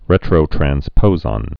(rĕtrō-trăns-pōzŏn)